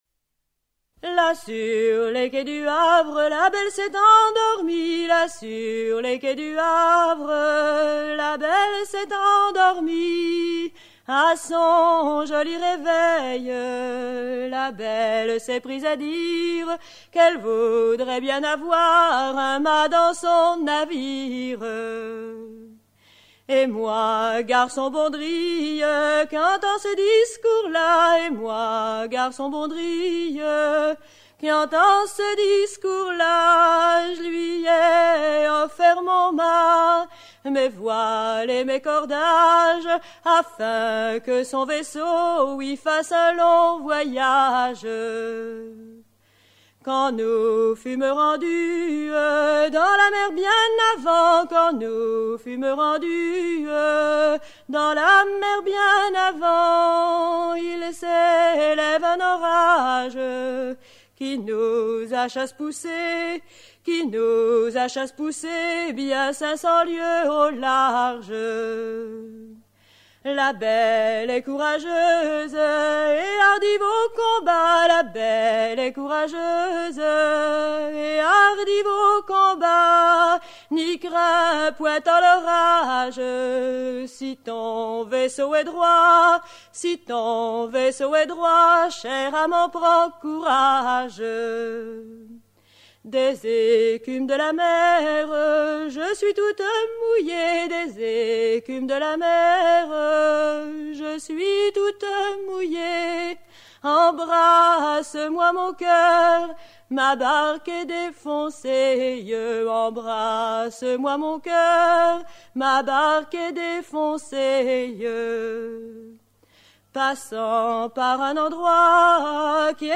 Genre laisse
Chants de marins traditionnels
Pièce musicale éditée